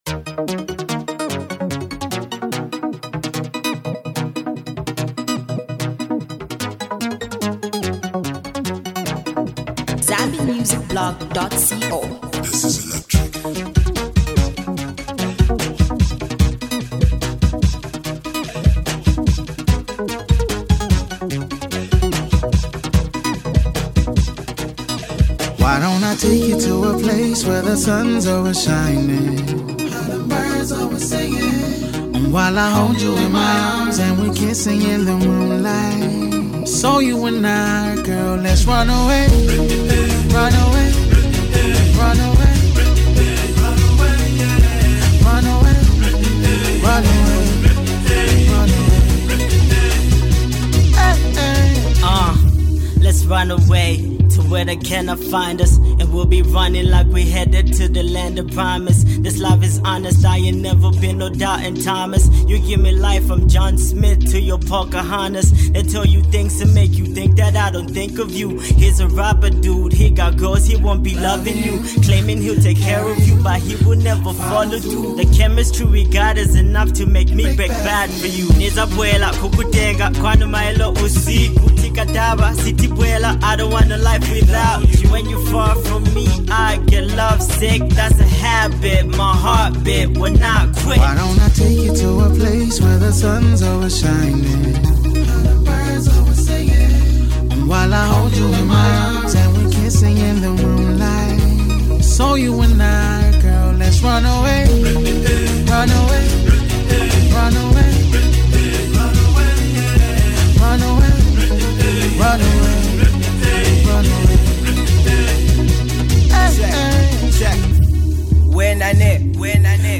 a Zambian Hip Hop music duo